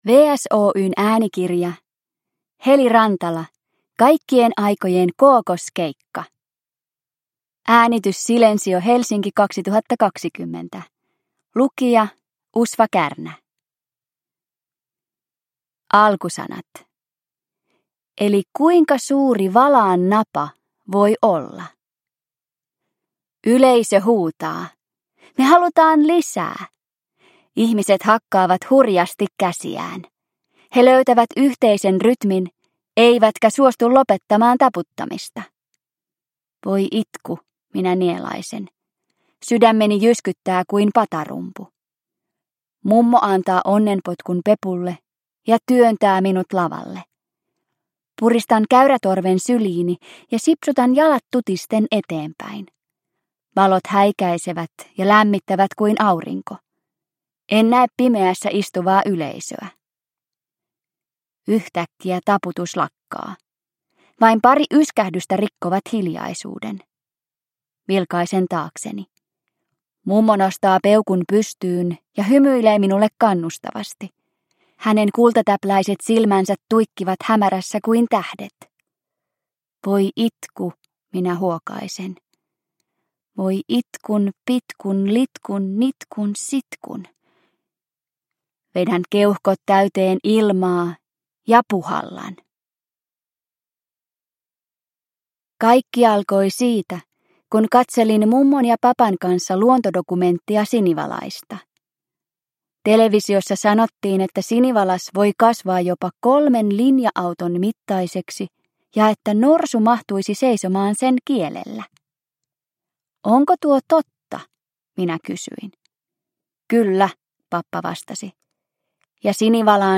Kaikkien aikojen kookoskeikka – Ljudbok – Laddas ner